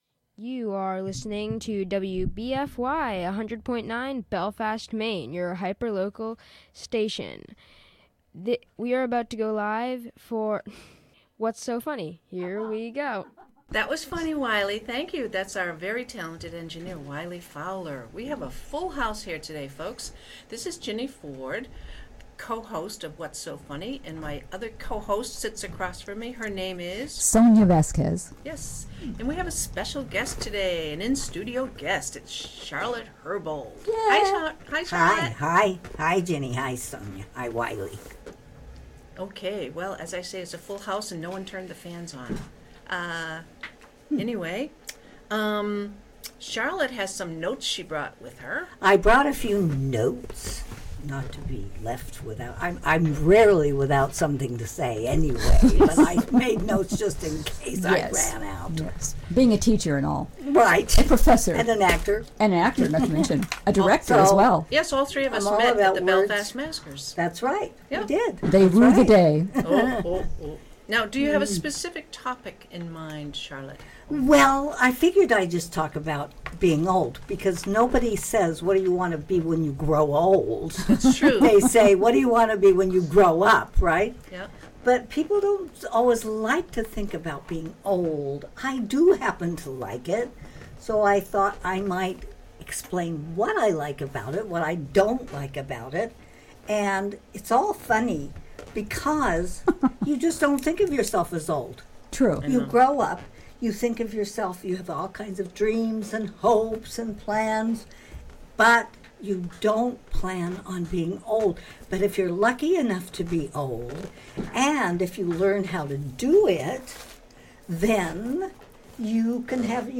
This program includes humorous stories and joke call-ins.